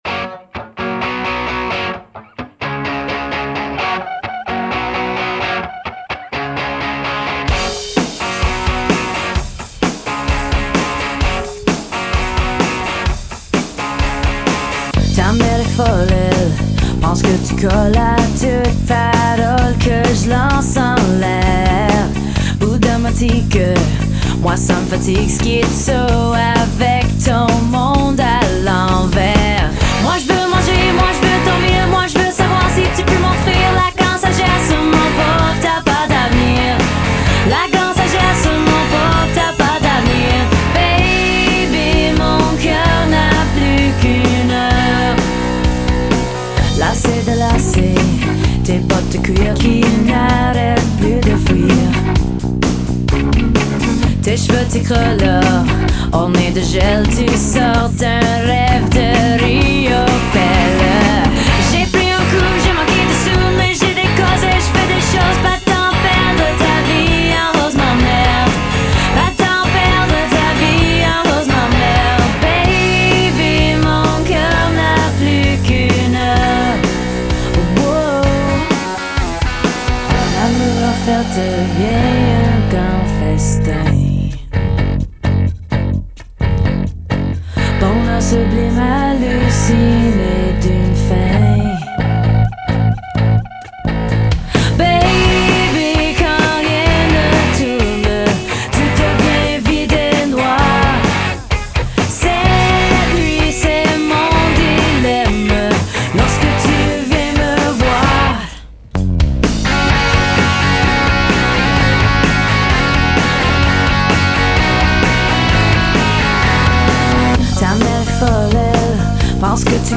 Vocals, Acoustic Guitar, Piano Percussion
Vocals, Electric Guitar, Acoustic Guitar, Bass
Drums, vocals